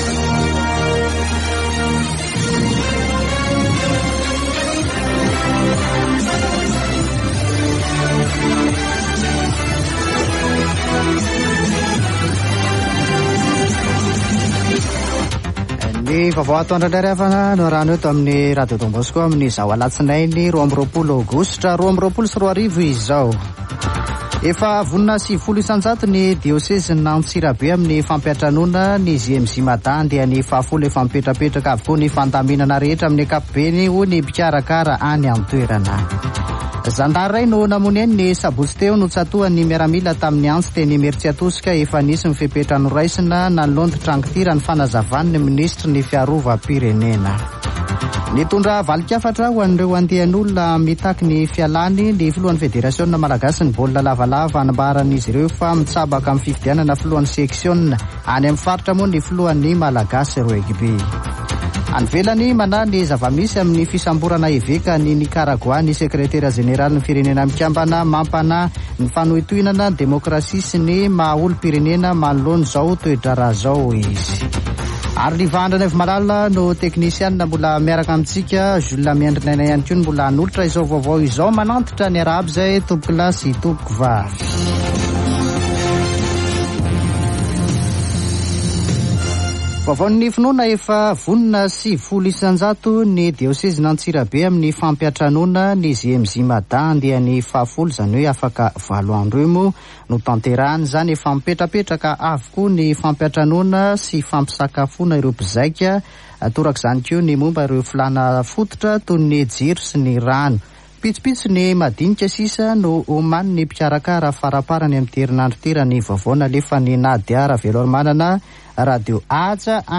[Vaovao antoandro] Alatsinainy 22 aogositra 2022